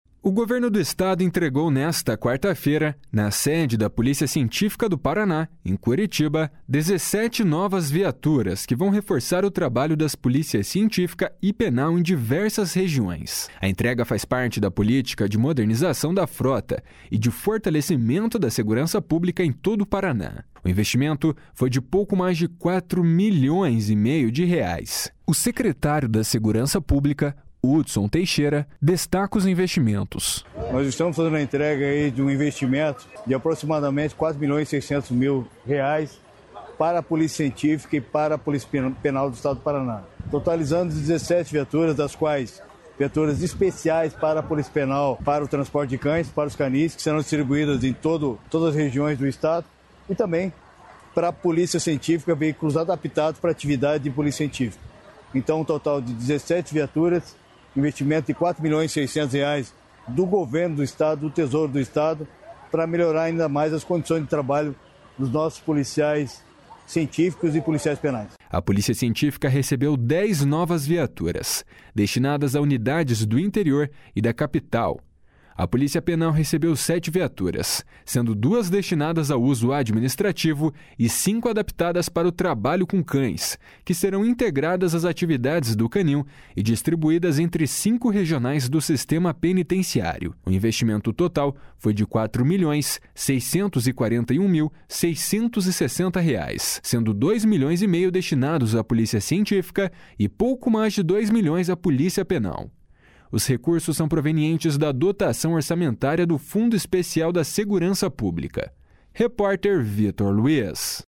O secretário da Segurança Pública, Hudson Teixeira, destaca o investimento. // SONORA HUDSON TEIXEIRA //